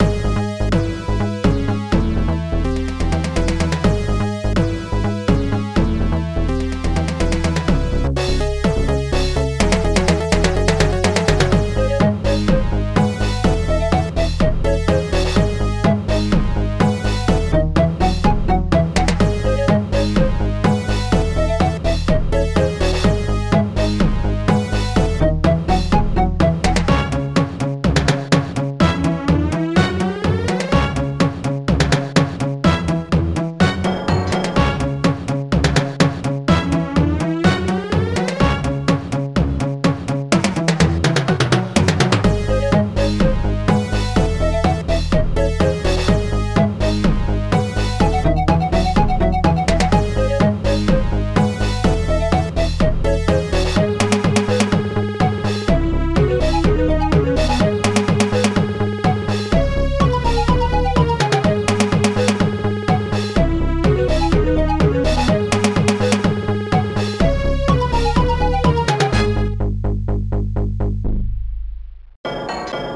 Protracker and family
ST-01:BIGBASS2
ST-01:SYNTH2
ST-01:VIOLON
ST-01:FLUTE
ST-01:BDRUM1
ST-01:SNARE2
ST-01:CYMBAL1
ST-01:HIT-ORCH
ST-01:STRINGS2